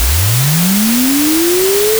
This is Barkhausen Hysteresis Sonification.
What you are hearing is the ‘snap’ of magnetic domains refusing to align. This is the physical sound of hysteresis.
Energy being dissipated as heat and noise because the material has memory.